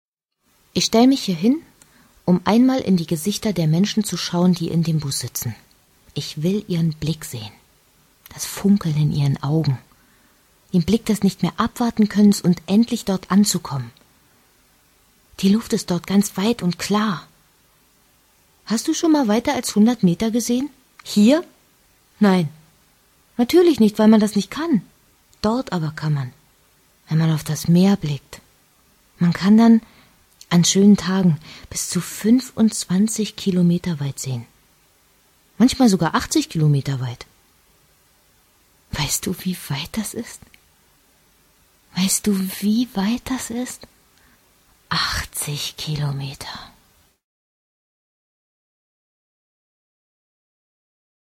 deutsche Sprecherin und Schauspielerin.
Sprechprobe: Sonstiges (Muttersprache):
german female voice over artist